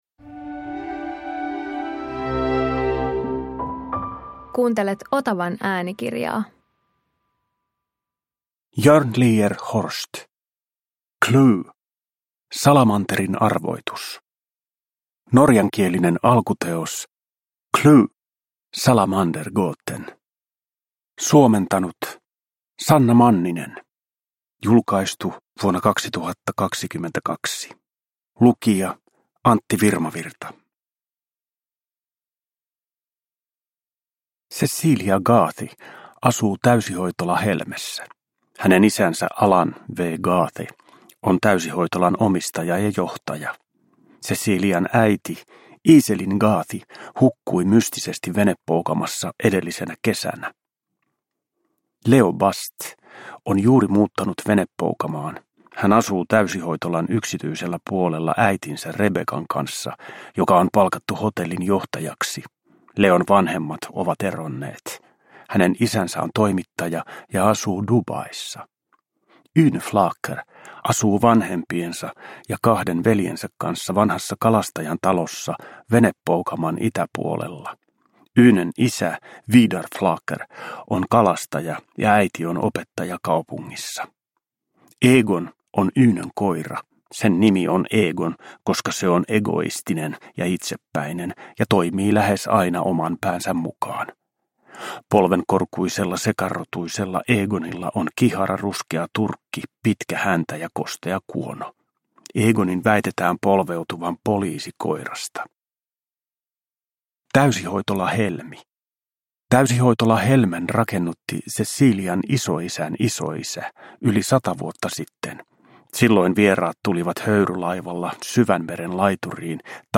CLUE – Salamanterin arvoitus – Ljudbok – Laddas ner